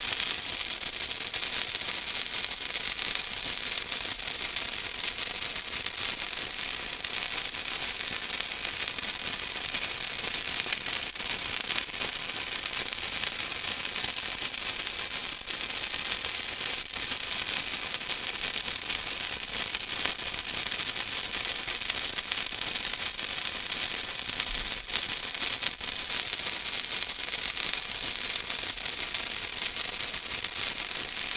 Fireworks Sparkler Burning, Loop.